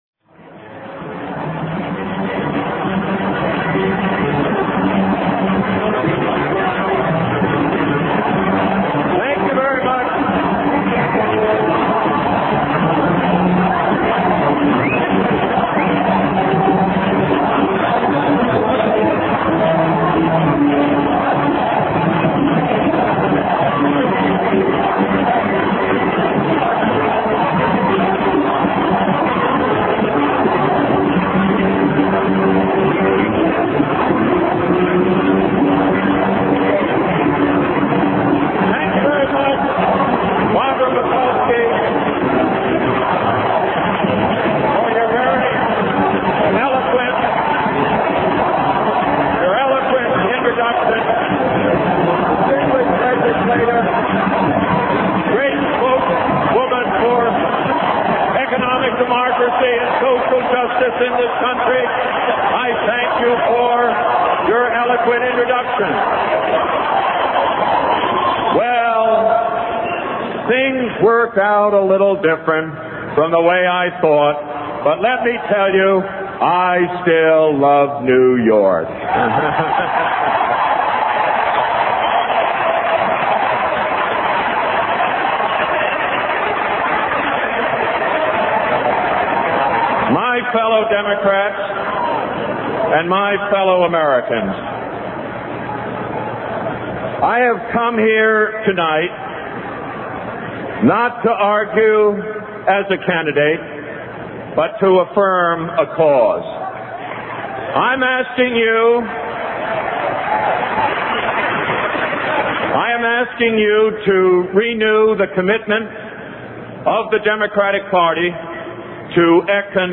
1980 Democratic National Convention Address